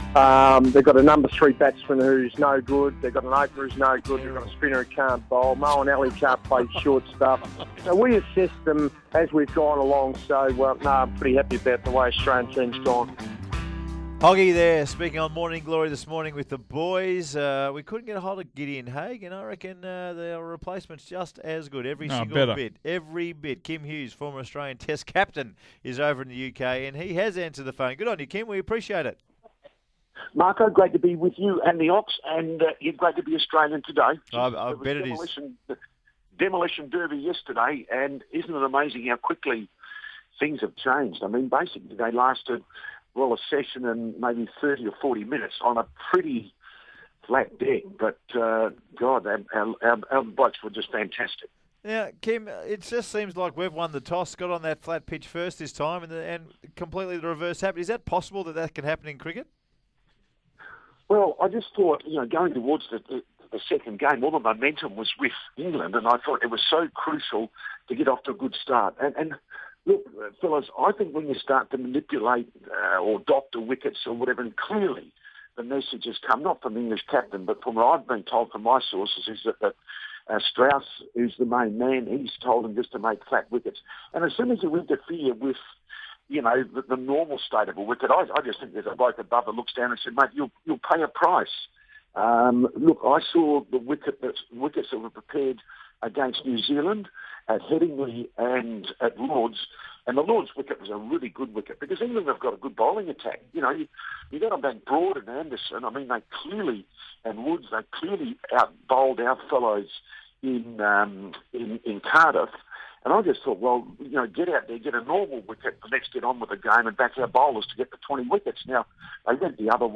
Former Australian Test captain Kim Hughes speaks to The Run Home about Australia's win in the Second Test.